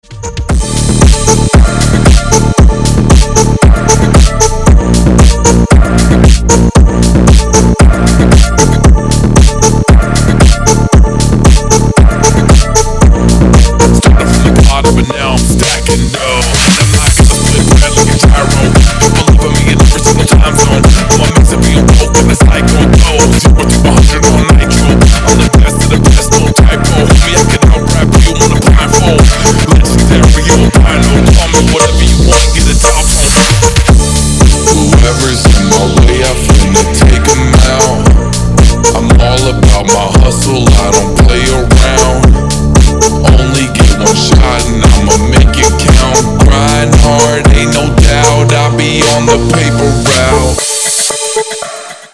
Громкие звонки, звучные рингтоны
басы на звонок